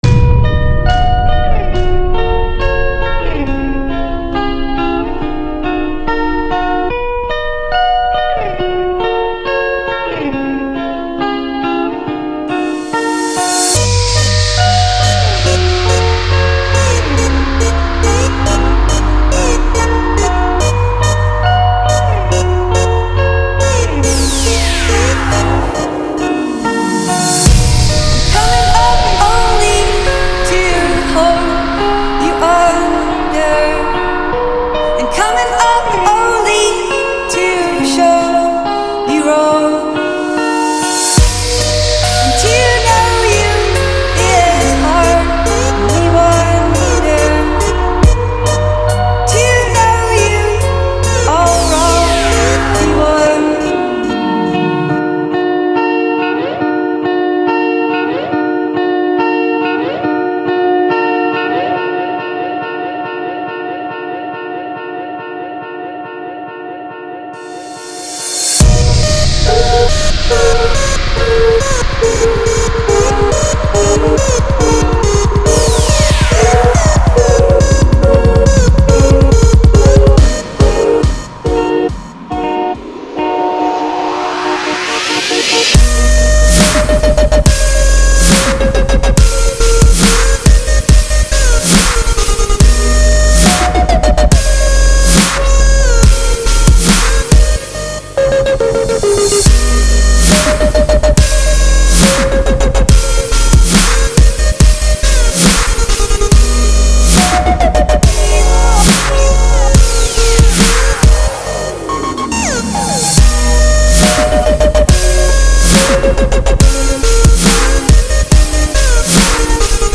Dubstep
avec grondement de la basse et la batterie lourde complexe.
Mais le rendu final est très bon .